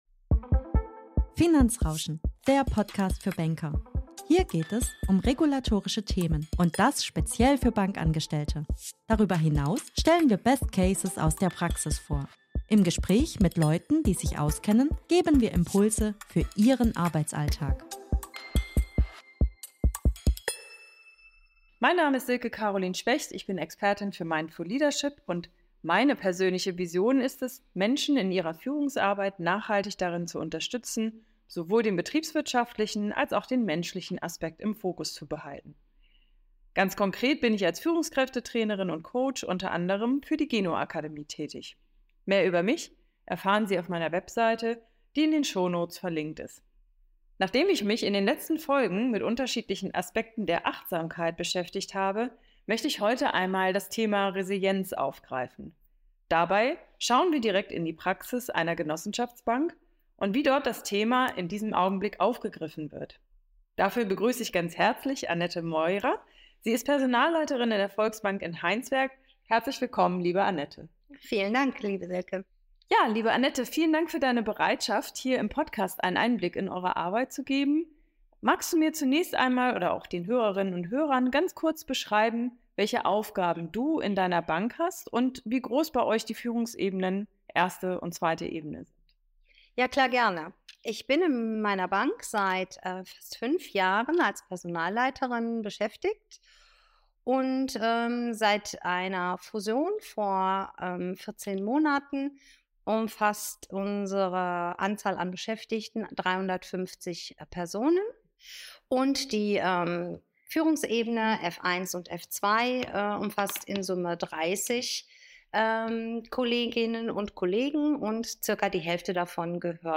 Beschreibung vor 2 Jahren Entdecken Sie jetzt in unserer neuesten Folge ein spannendes Gespräch über Resilienz in der Bankenwelt!